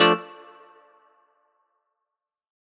Guitar Zion 2.wav